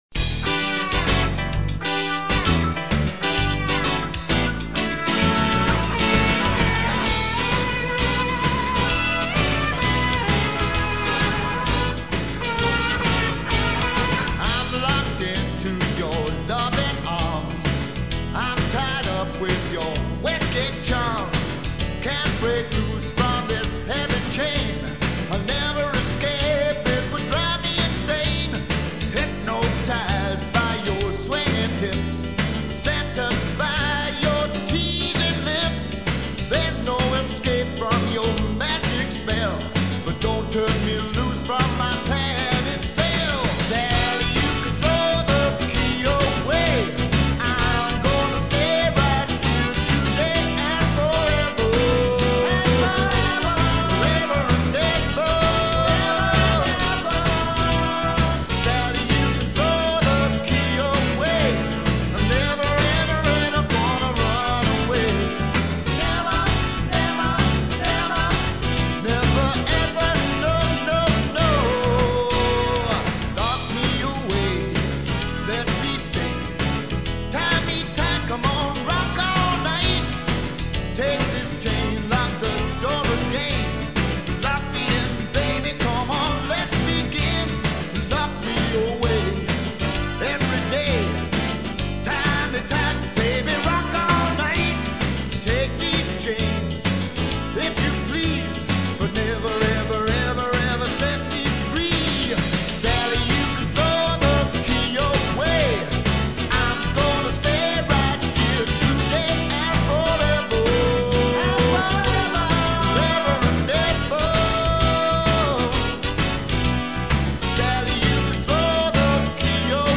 Guitar
Kbds